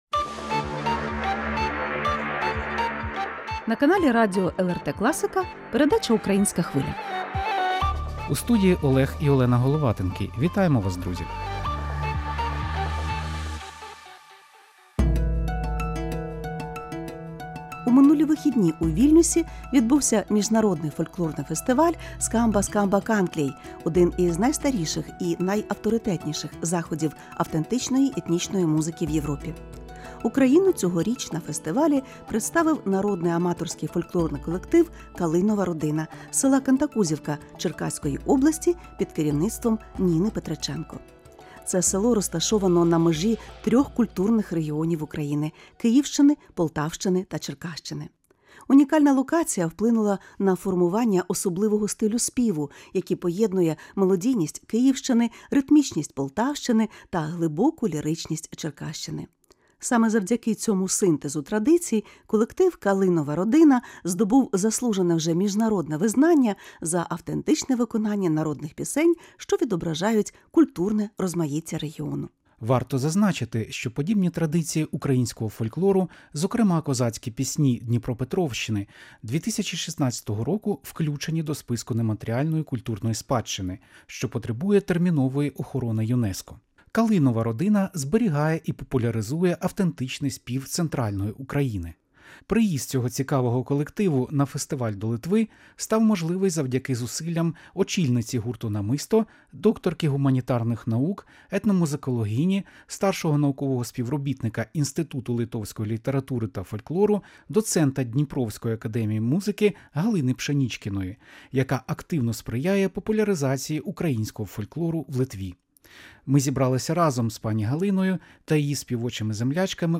В «Українській Хвилі» - автентичні голоси Центральної України.
Разом з гостями студії говоримо про унікальну традицію народного співу, що народилася на перетині трьох культурних регіонів, - Київщини, Полтавщини та Черкащини.